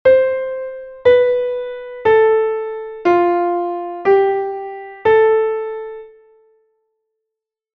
Es decir, se superpone la métrica de 2/4 sobre la ternaria de 3/4.
Ejemplo de hemiola en una partitura de cuatro compases de tres por cuatro.
Audio de elaboración propia. Hemiola. (CC BY-NC-SA)